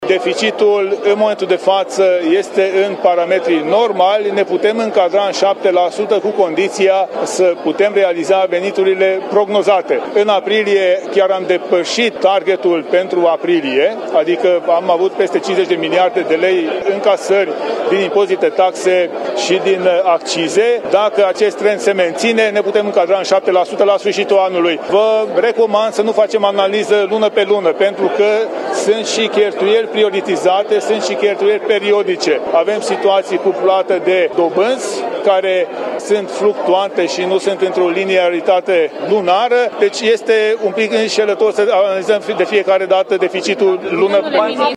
Ministrul Finanțelor, Tanczos Barna: În aprilie am depășit ținta pentru acea lună, „adică am avut peste 50 de miliarde de lei din impozite, taxe și accize”